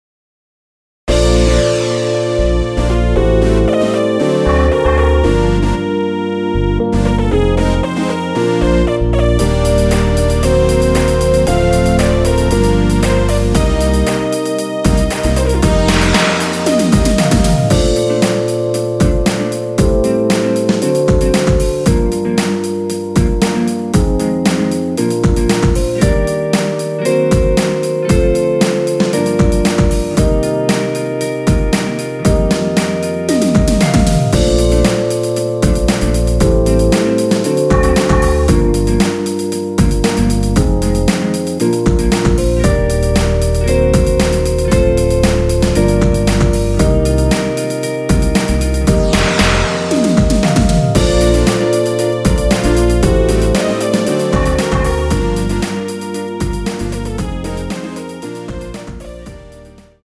원키에서(-1)내린 MR입니다.
Bb
앞부분30초, 뒷부분30초씩 편집해서 올려 드리고 있습니다.
중간에 음이 끈어지고 다시 나오는 이유는